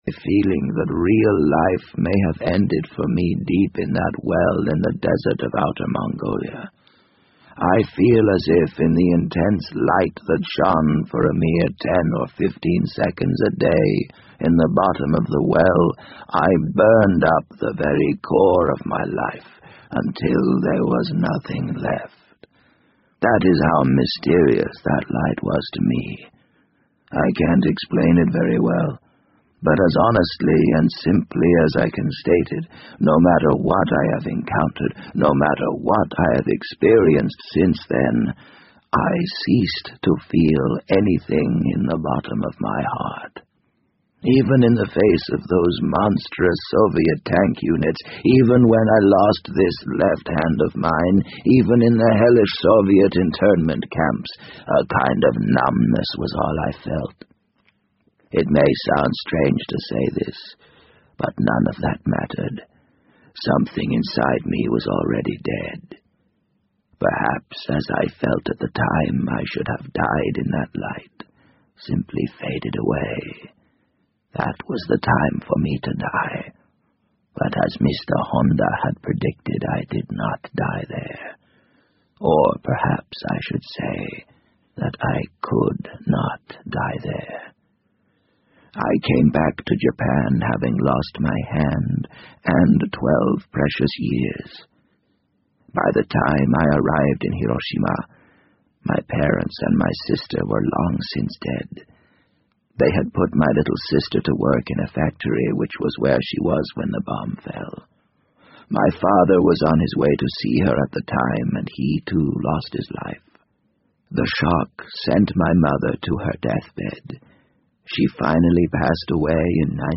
BBC英文广播剧在线听 The Wind Up Bird 005 - 5 听力文件下载—在线英语听力室